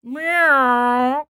Index of /90_sSampleCDs/ILIO - Vocal Planet VOL-3 - Jazz & FX/Partition H/3 VOCAL FX
BN-SING 0110.wav